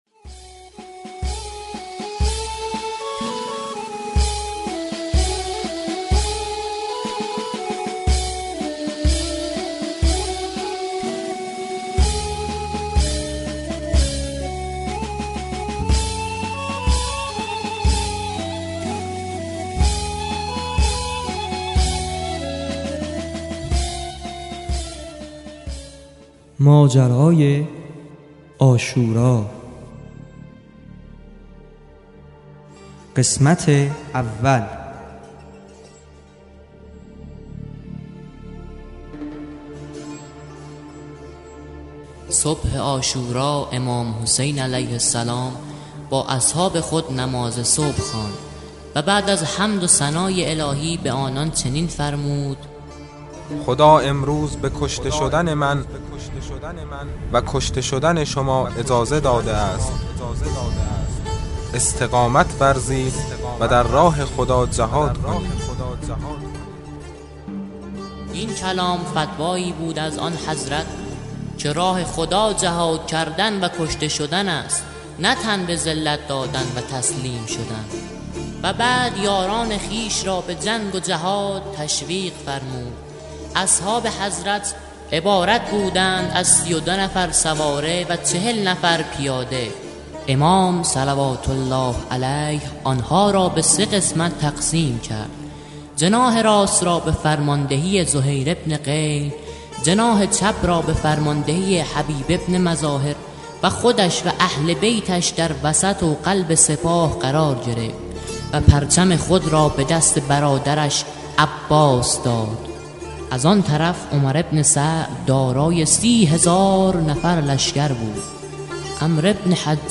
داستان صوتی ماجرای عاشورا از نماز صبح تا حرکت اسرا در عصر عاشورا